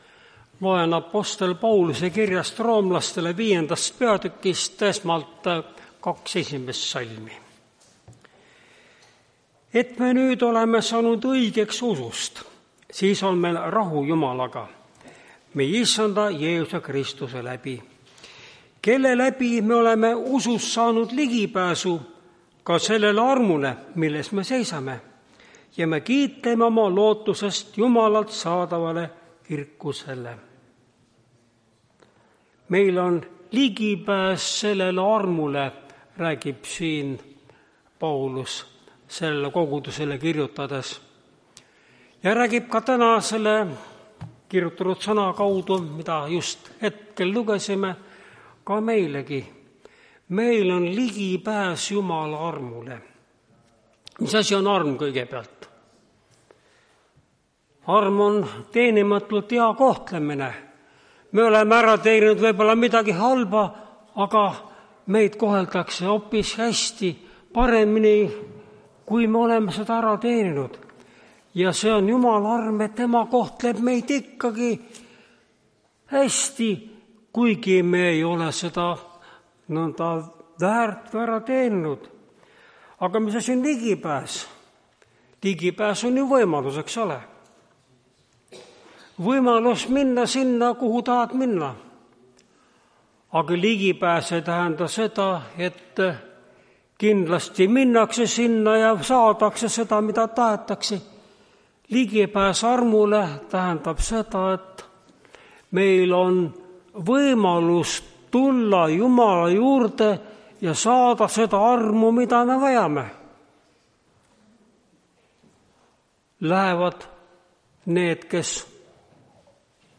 Tartu adventkoguduse 29.11.2025 teenistuse jutluse helisalvestis.